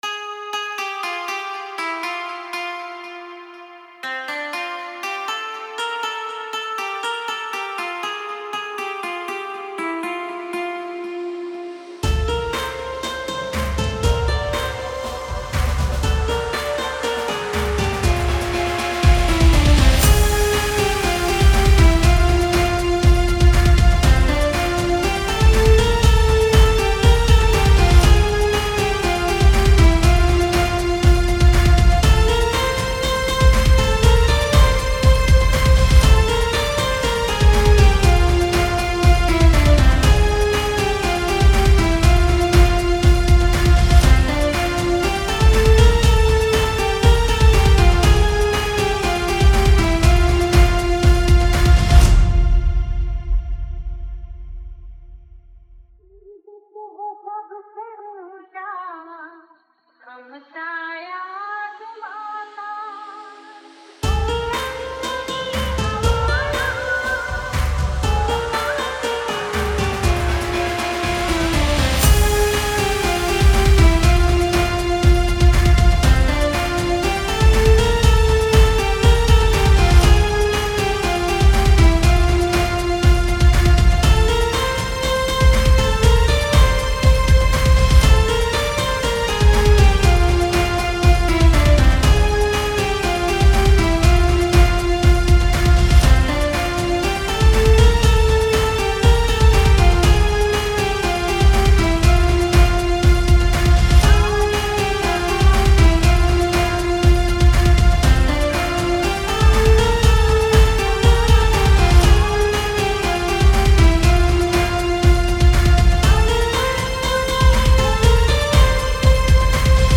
Desh Bhakti Dj Remix Song Songs Download